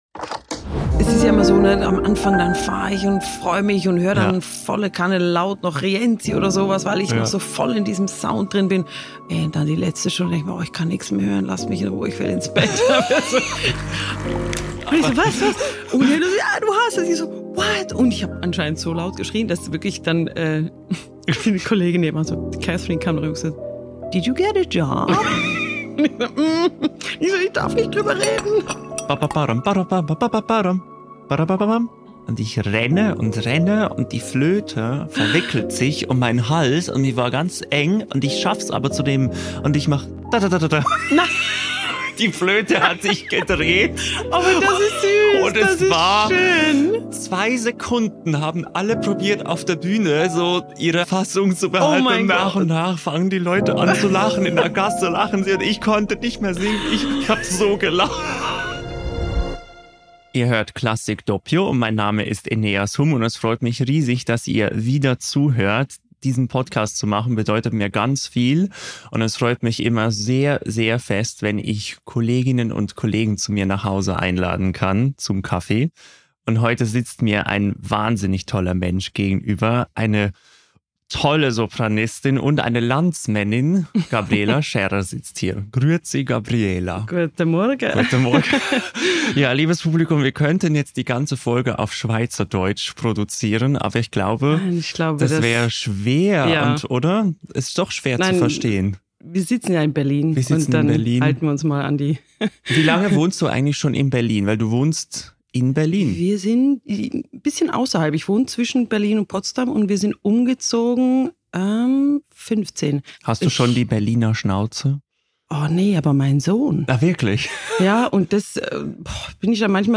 Bei einem Americano aus der Röststätte Berlin plaudert sie über die Magie der Wagner-Welt, Herausforderungen auf der Bühne und ihre ganz persönliche Sicht auf die Klassik.